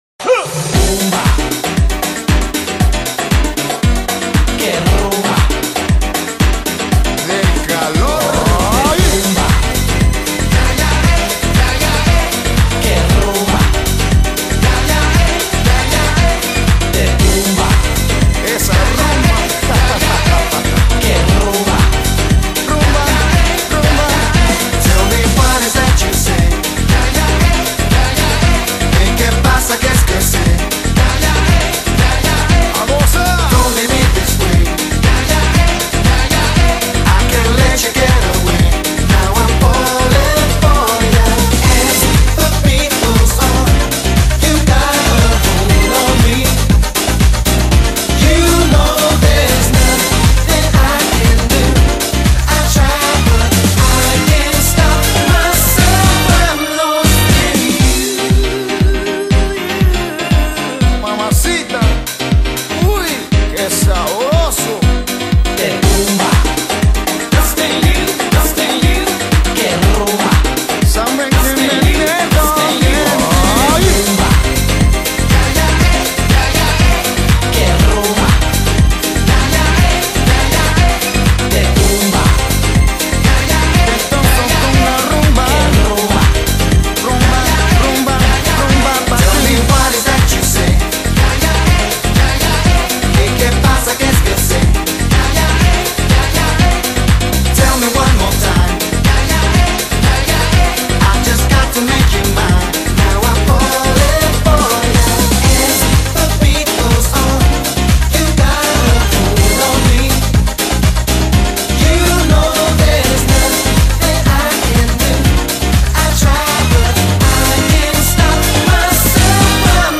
录DANCE），是一张具有曼波神韵的音乐专辑。
门金曲，旋律性强而富有动感，散发着无穷的时尚气息。